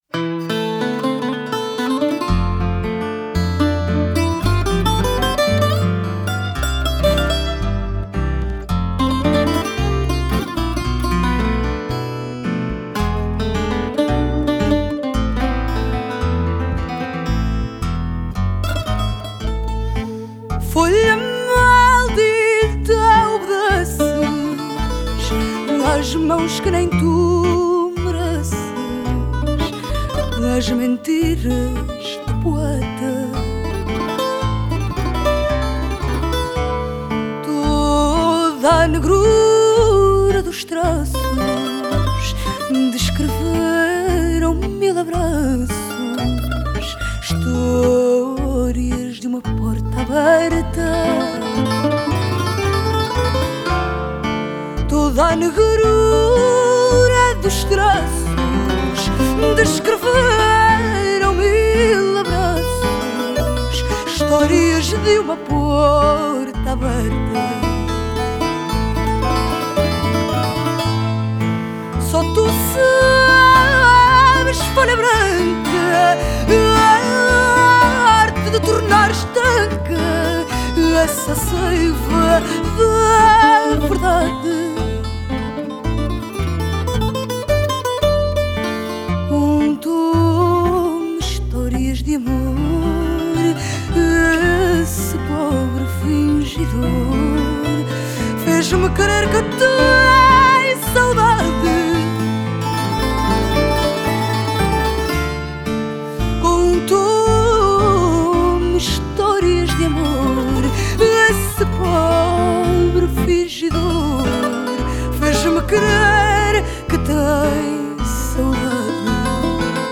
Genre: Fado, Folk, Portuguese music